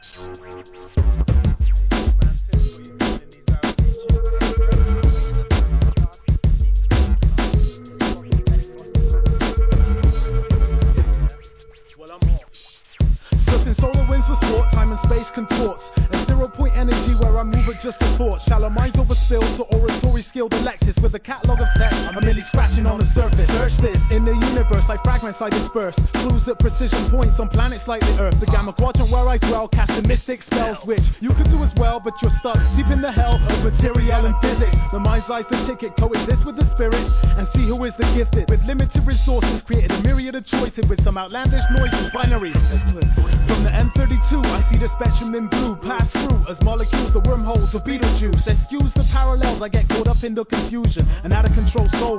HIP HOP/R&B
1999年、UKアンダーグランド!!